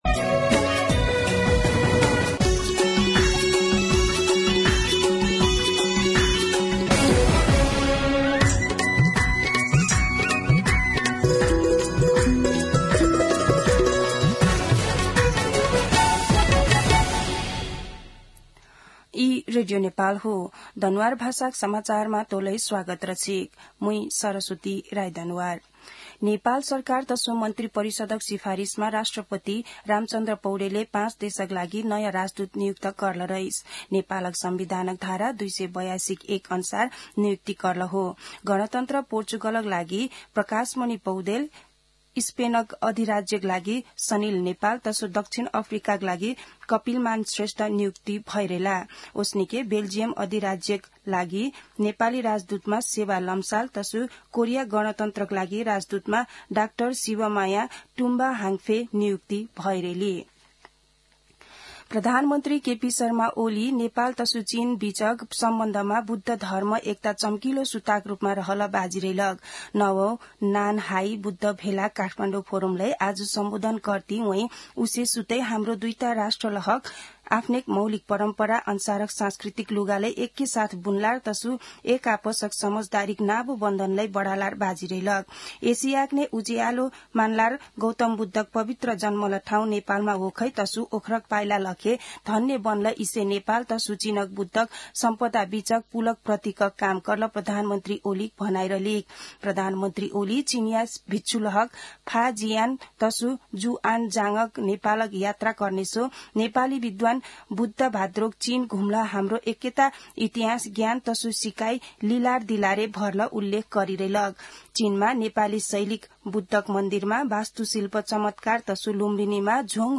An online outlet of Nepal's national radio broadcaster
दनुवार भाषामा समाचार : २९ मंसिर , २०८१
Danuwar-news.mp3